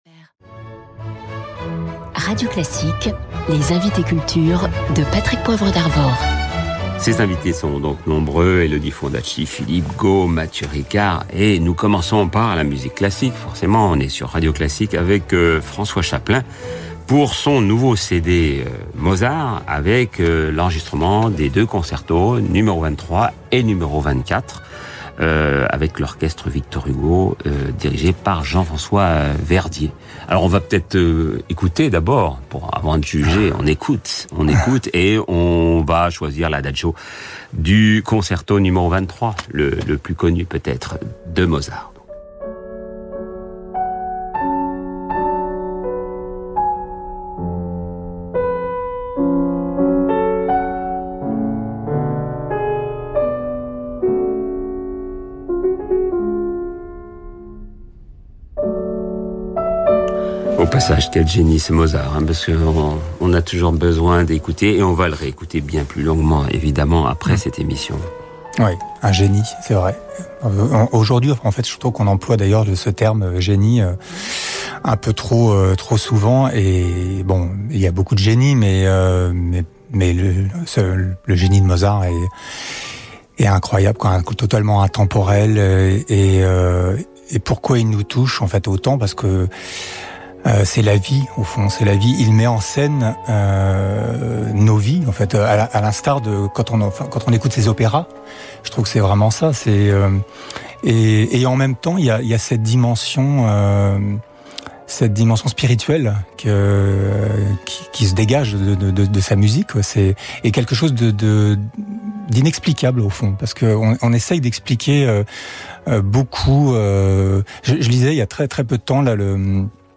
Patrick Poivre d’Arvor rend hommage au livre de Carole Sorreau sur Radio Classique (6ème minute et 5 secondes) :